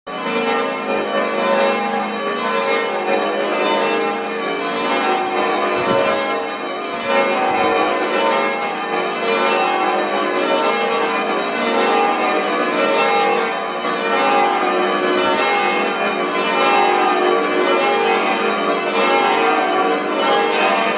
The Chapel bells ringing to celebrate the 62nd anniversary of HM the Queen’s accession - recorded in Mob Quad.